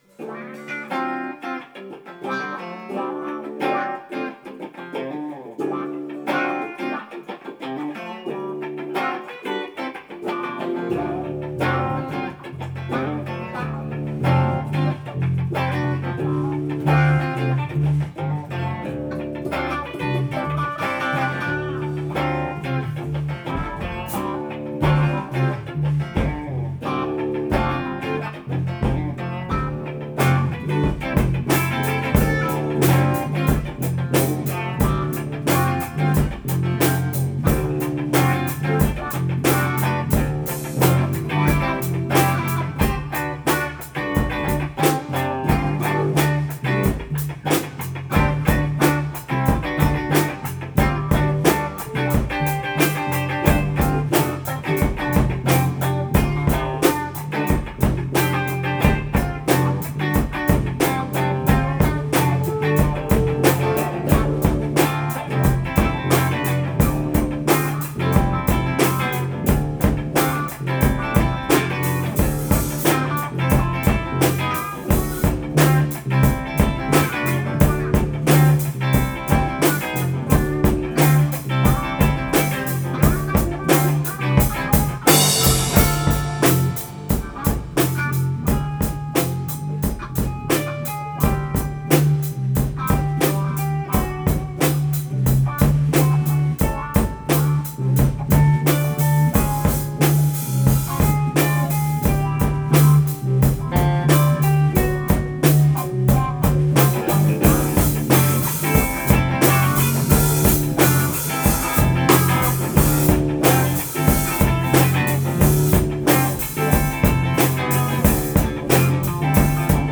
Tempo: 86 bpm / Datum: 17.03.2012